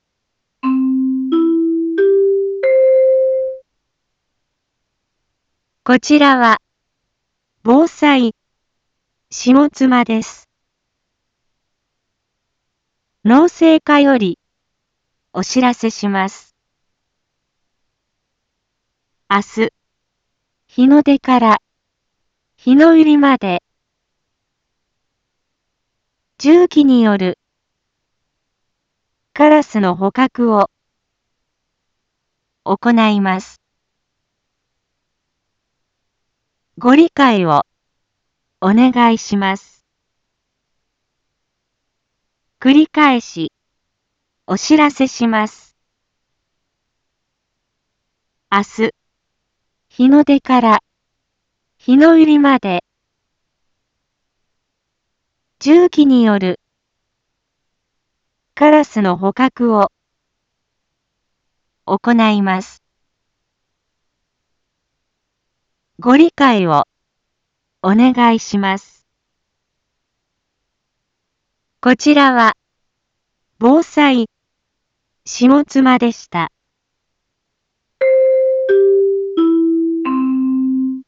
一般放送情報
Back Home 一般放送情報 音声放送 再生 一般放送情報 登録日時：2022-02-05 18:01:21 タイトル：有害鳥獣捕獲についてのお知らせ インフォメーション：こちらは、防災下妻です。